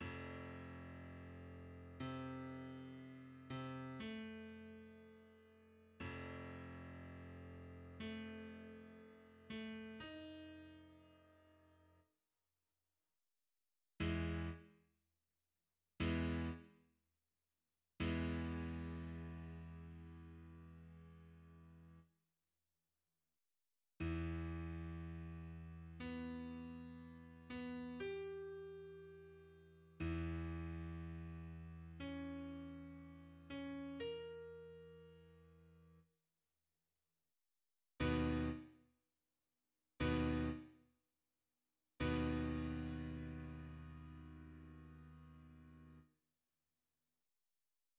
The Introduzione is a short Adagio in 6
This replaced an earlier, longer middle movement, later published as the Andante favori, WoO 57.[4] The music gradually gets more agitated before calming down to transition into the rondo third movement.